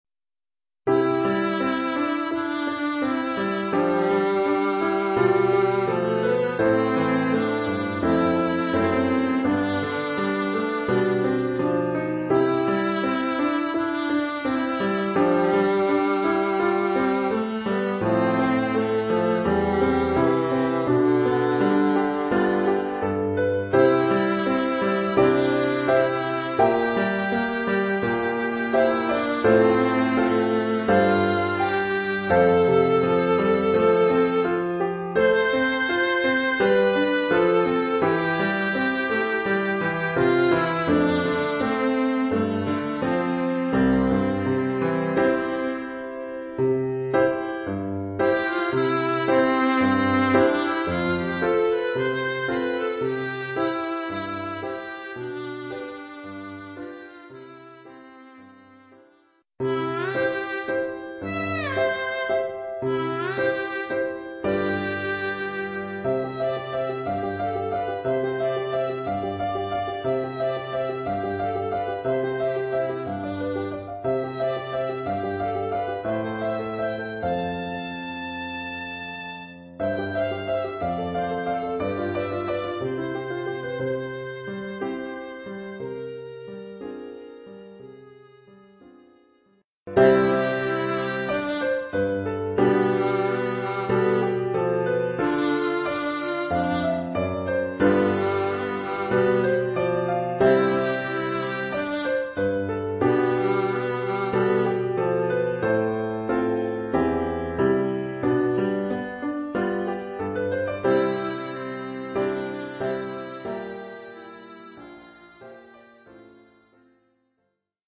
Oeuvre pour alto et piano.